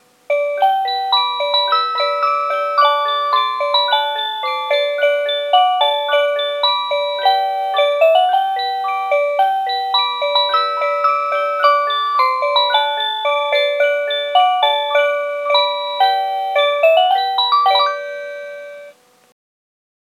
12-Cuckoo-Tune.mp3